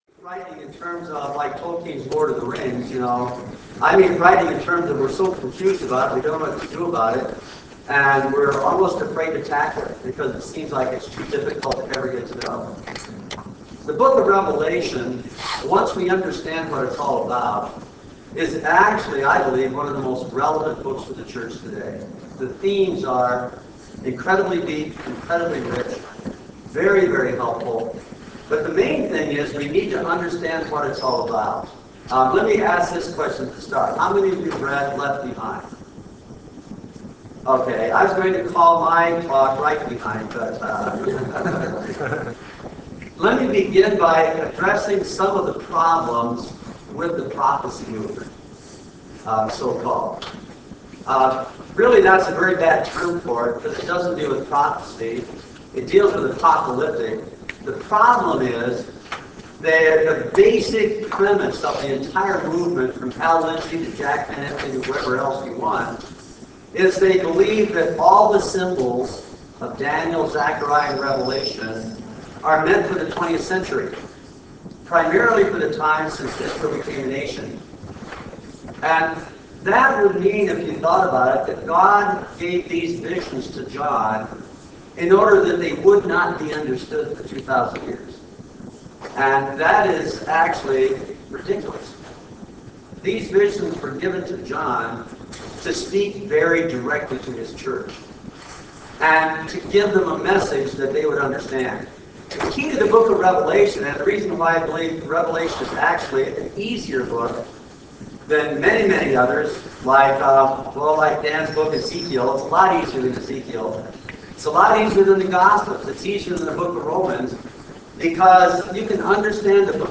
The Power & the Promise of the Word Conference